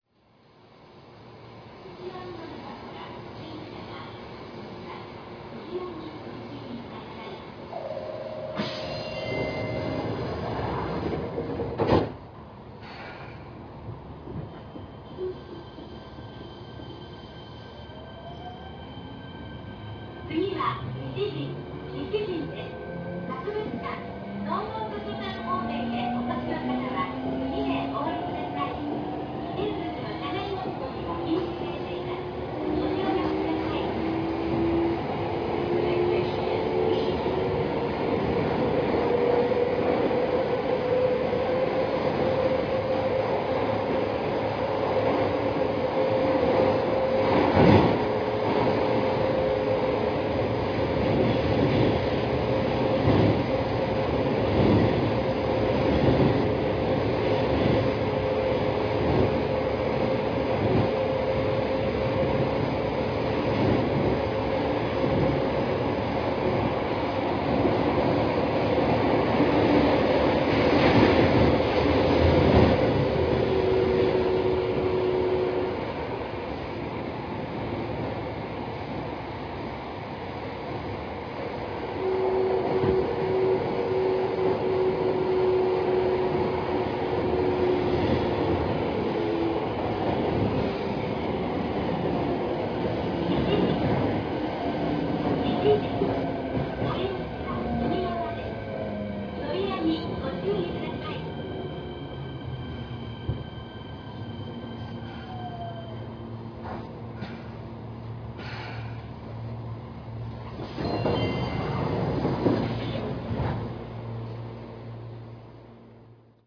走行音(2レベルIGBT)[fu1000nb.ra/228KB]
制御方式：VVVFインバータ制御(日立2レベルIGBT・1C4M2群制御？)
非同期音の高さがまったく違うのが分かります。